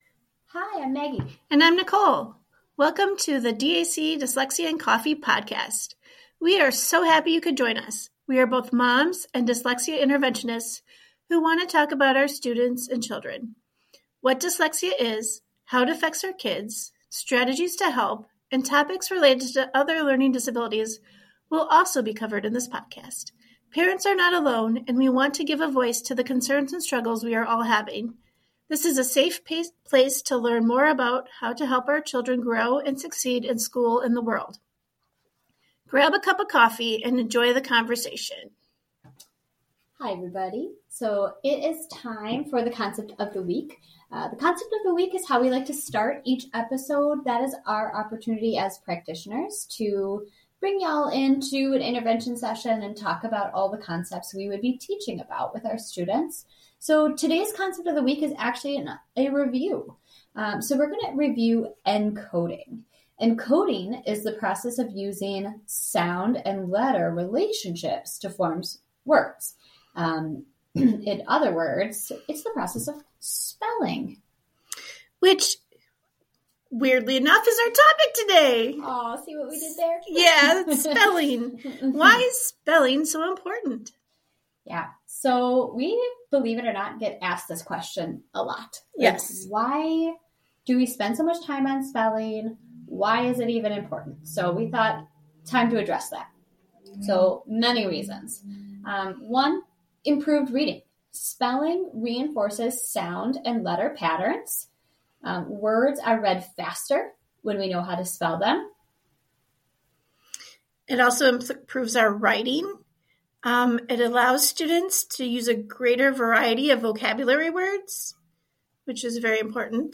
We are both moms and dyslexia interventionists who want to talk about our students and children.